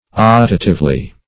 Search Result for " optatively" : The Collaborative International Dictionary of English v.0.48: Optatively \Op"ta*tive*ly\, adv. In an optative manner; with the expression of desire.